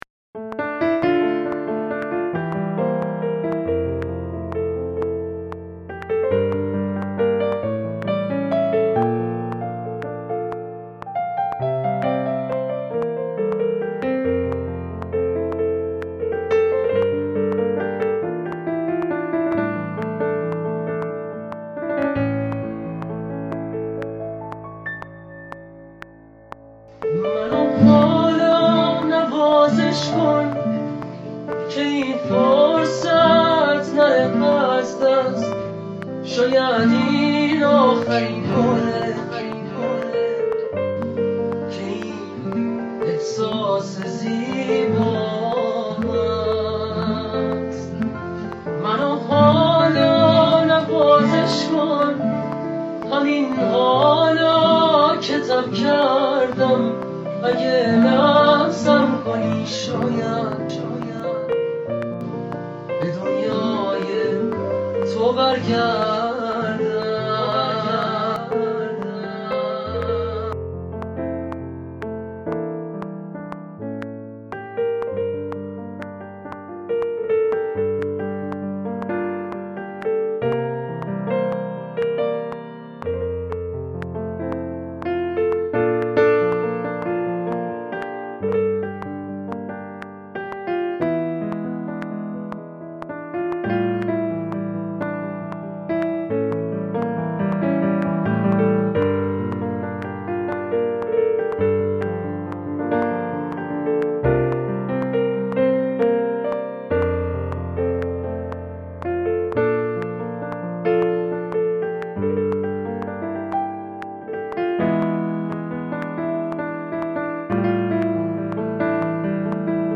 تو خونه با یه پیانو اجرا کرده!
اهنگ عاشقانه غمگین